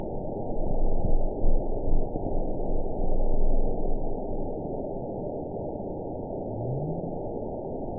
event 920041 date 02/12/24 time 22:58:46 GMT (1 year, 4 months ago) score 9.71 location TSS-AB05 detected by nrw target species NRW annotations +NRW Spectrogram: Frequency (kHz) vs. Time (s) audio not available .wav